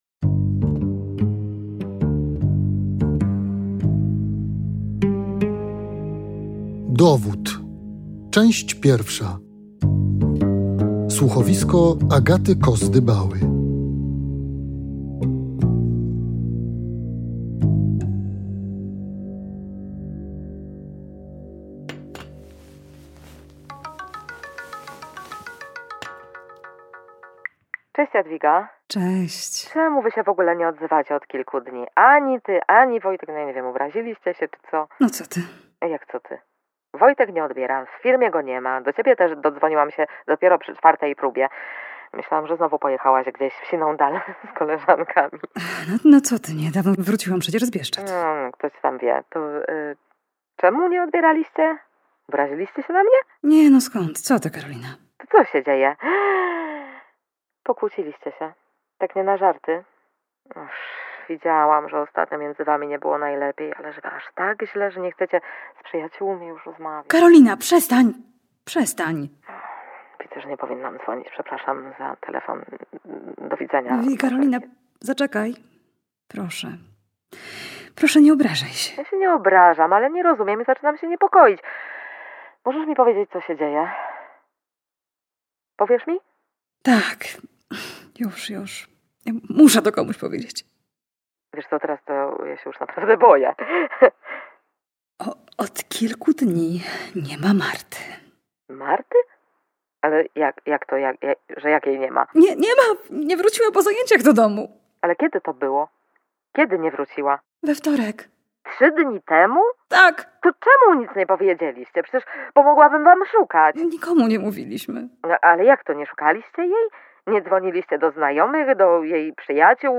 Zapraszam Państwa na spotkanie z bohaterami nowego słuchowiska